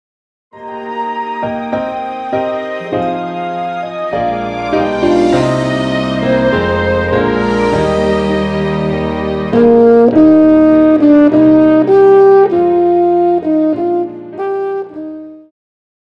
Classical
French Horn
Band
Traditional (Folk),Classical Music
Instrumental
Only backing